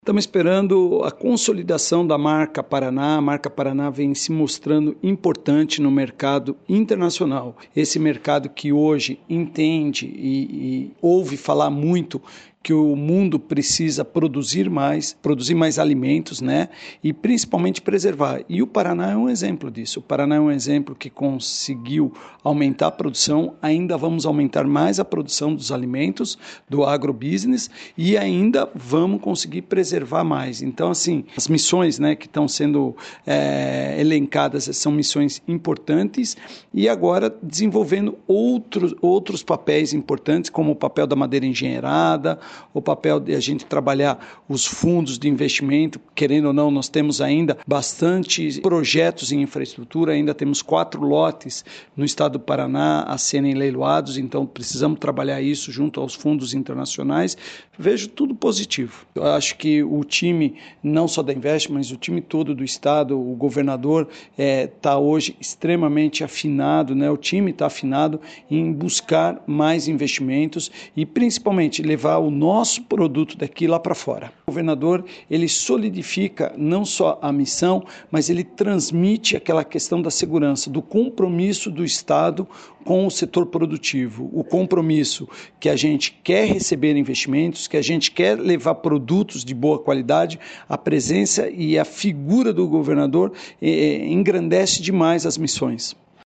Sonora do diretor-presidente da Invest Paraná, Eduardo Bekin, sobre a missão ao Nebraska, primeira agenda internacional do Governo do Paraná em 2024